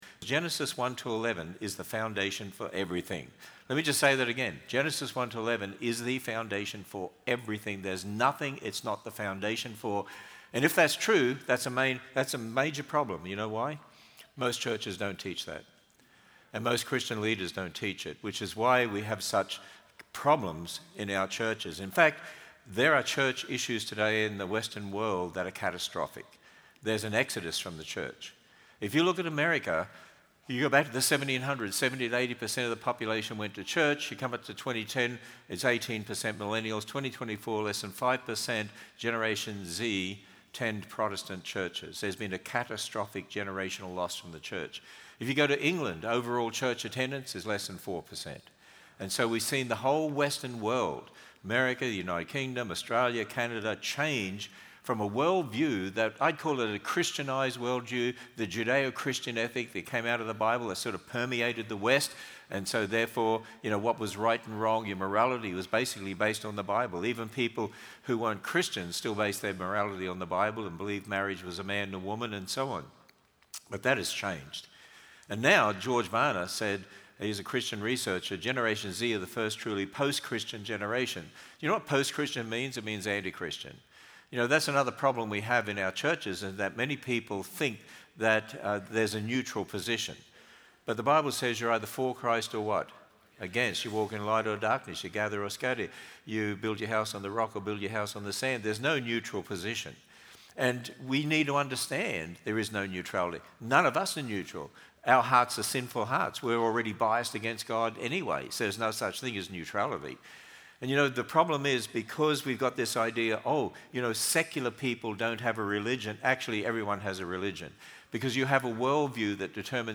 Sermon Overview